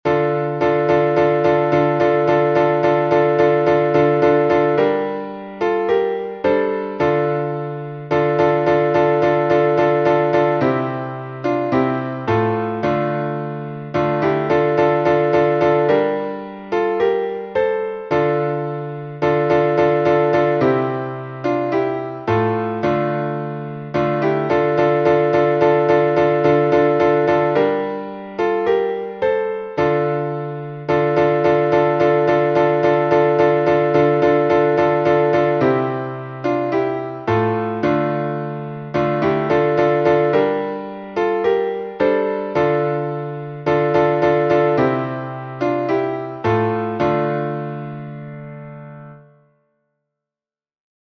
Напев Ильинского скита на Афоне